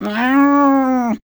Divergent / mods / Soundscape Overhaul / gamedata / sounds / monsters / cat / 1.ogg